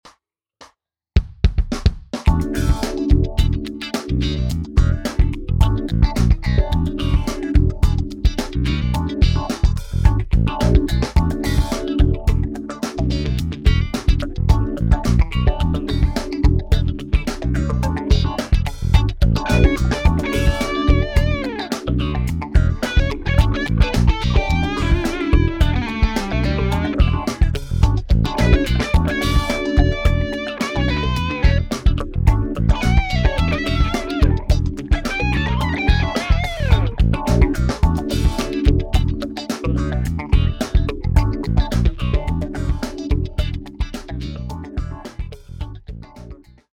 Linke Gitarre 4 Stage Phaser Script Rechte Gitarre 2 Stage Phaser Script, Speed etwas langsamer, habe auch etwas Wammybar benutzt Mitte Gitarre 4 Stage Phaser Script Dazu noch ein bisl Delays und Reverb.